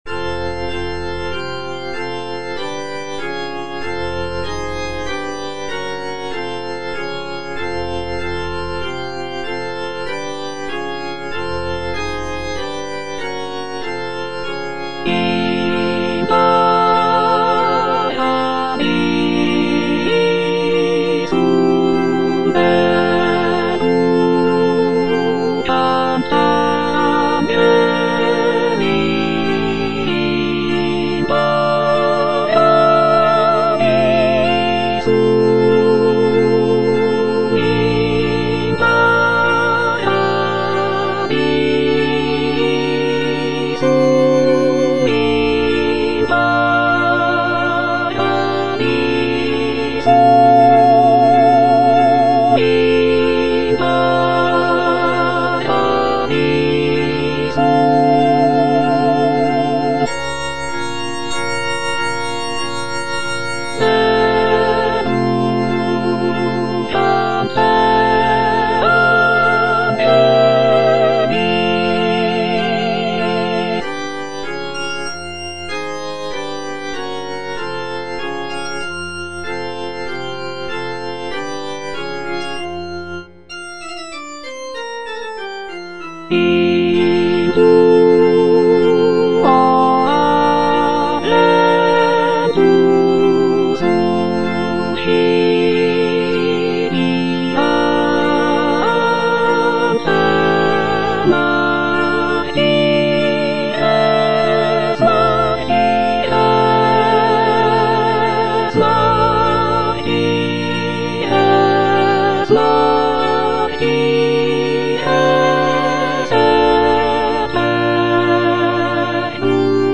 soprano I) (Emphasised voice and other voices